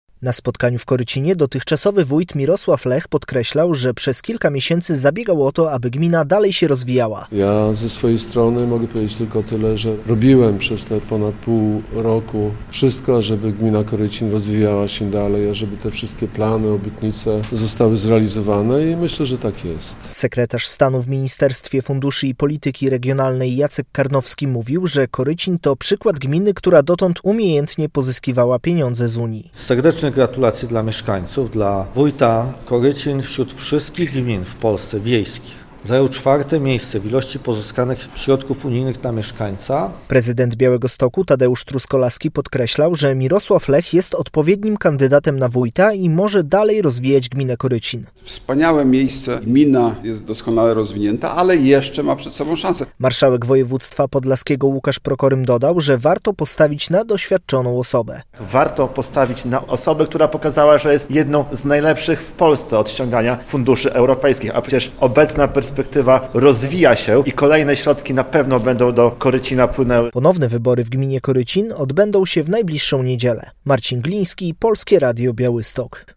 relacja
Na spotkaniu w Korycinie dotychczasowy wójt Mirosław Lech podkreślał, że przez kilka miesięcy zabiegał o to, aby gmina dalej się rozwijała.